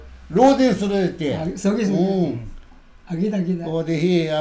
たとえば，以下の会話に見られるように，アゲとソゲは，同じ場面で同時に使われることがあります。
このとき，注意して聞いてみると，まず，「ア（ゲ）」といいかけて，それを止めてから，「ソゲソゲ（そうそう）」と言い直しています。